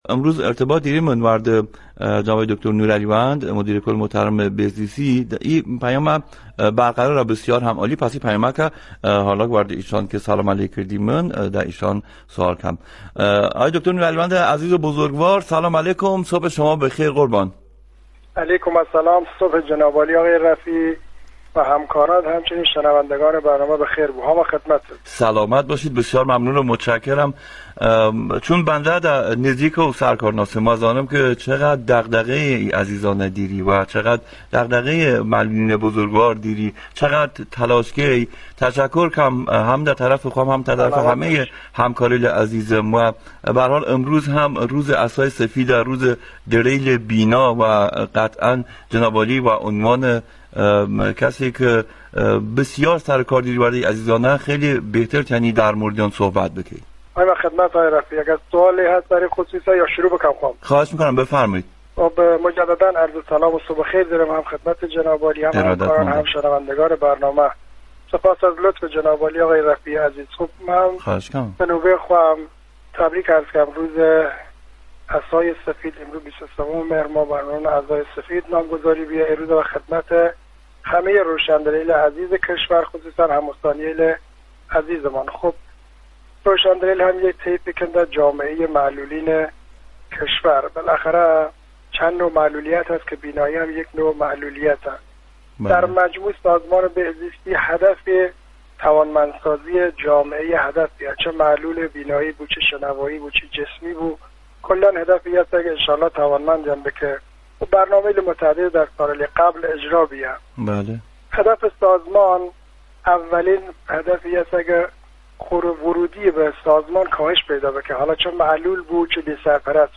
صوت| گفتگوی تلفنی مدیر کل بهزیستی ایلام با برنامه رادیویی شوکیانه